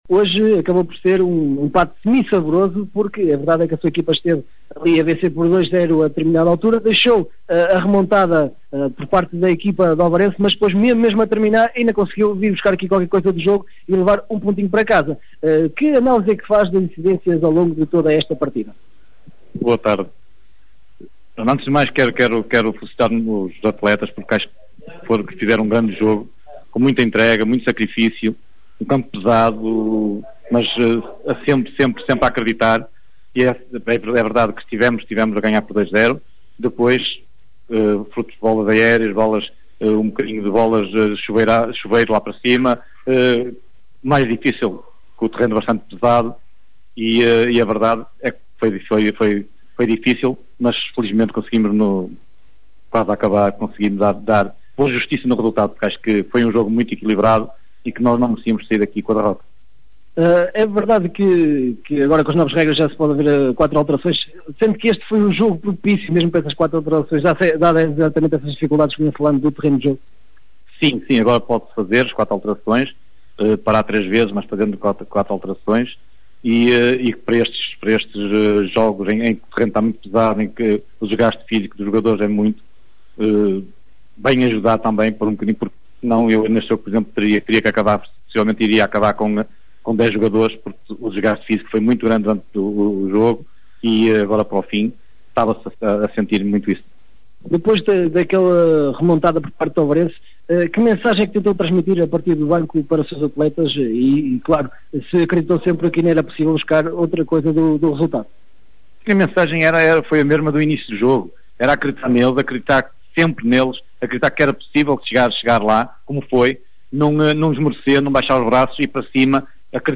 Ouça as declarações dos técnicos: